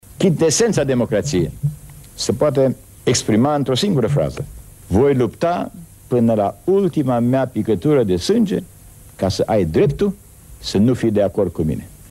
Ion Rațiu într-o dezbatere televizată din 1990, anul primelor alegeri libere din România modernă, a surprins esența democrației în câteva cuvinte.